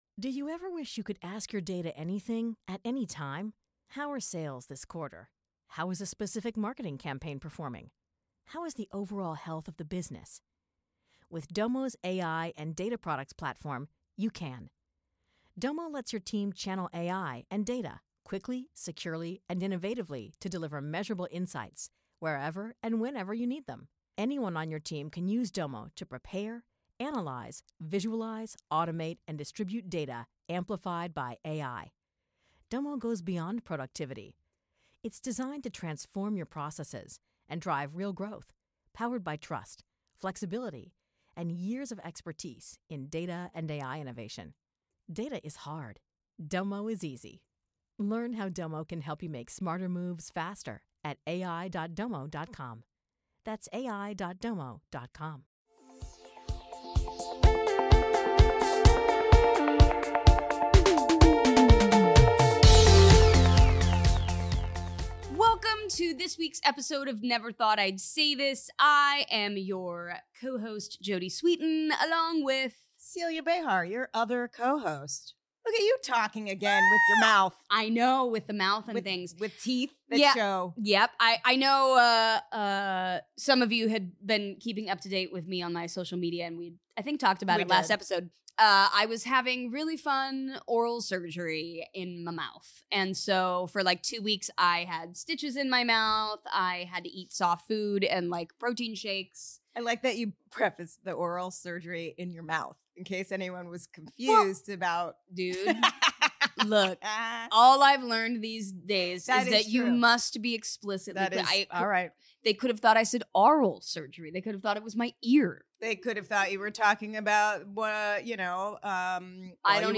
Spoiler alert: You can tell. Please forgive us for the sound issues.